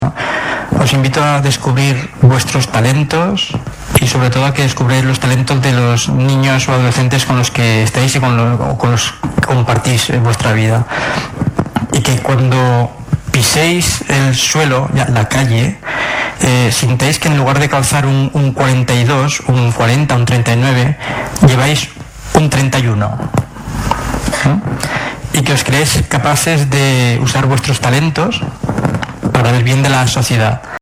El prestigioso ponente desarrolló así una entretenida charla, rematada por un tiempo para el intercambio de opiniones y experiencias, presenciales y a través de Twitter a partir de su tesis fundamentada en que “todos tenemos diversos talentos, lo que no significa salir en un concurso de televisión. Es algo que te hace tender hacia tu felicidad y que se debe usar para colaborar y contribuir a construir una sociedad mejor”.